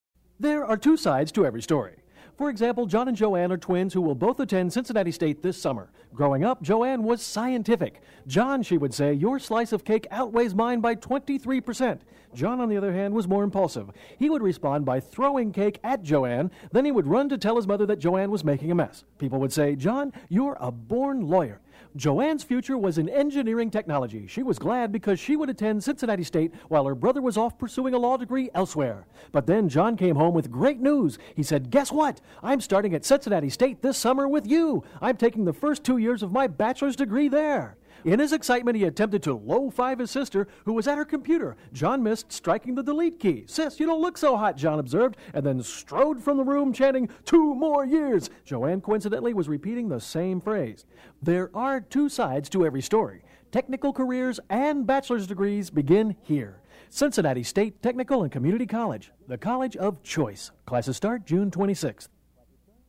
Transcript for "Two Sides" radio ad, 1994